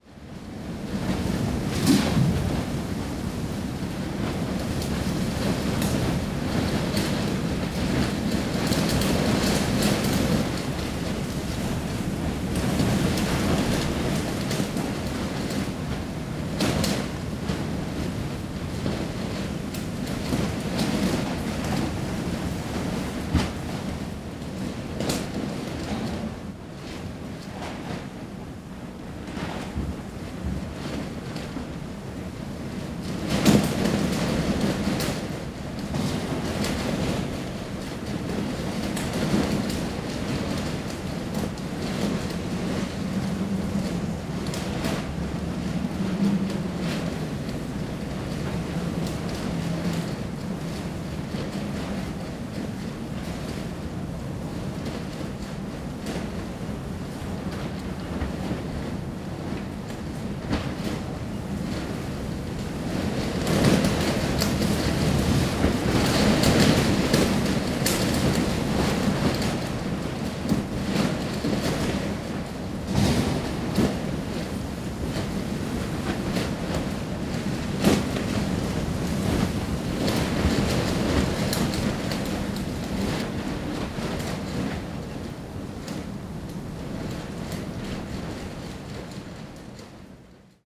Звуки шторма
Шторм в океане с брызгами волн